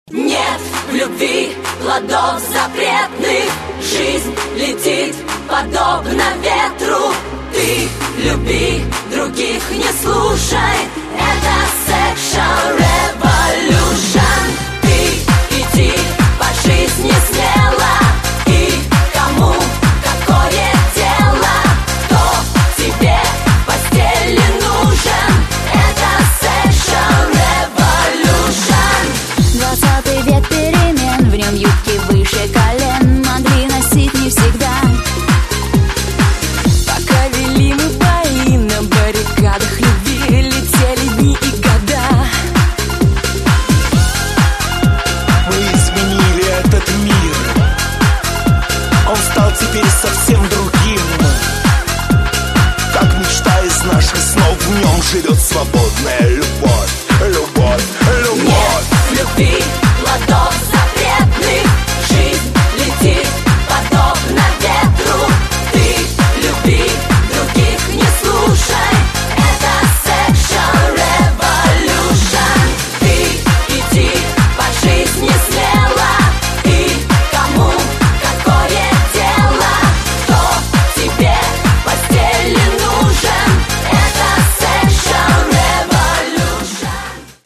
Танцевальные
Русская версия знаменитого хита шведской поп-группы.